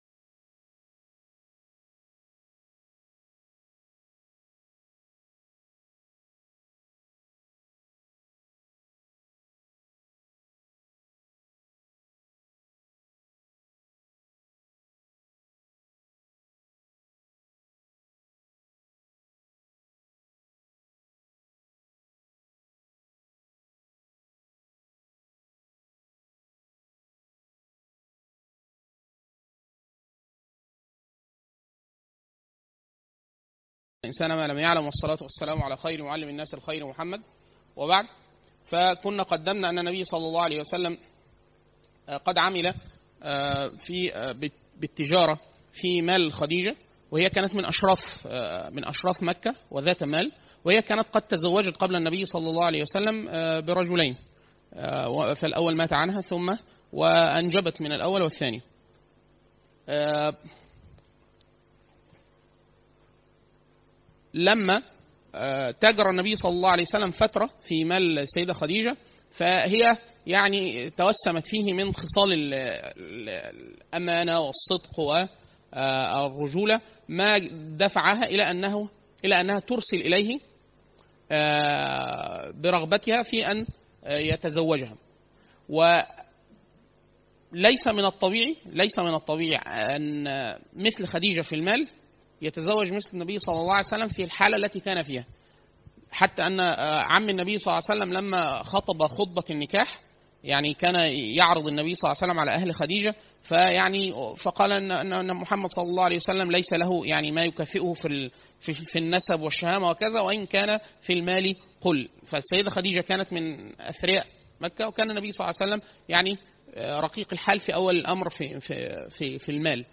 (3) العهد المكي (المحاضرة الثالثة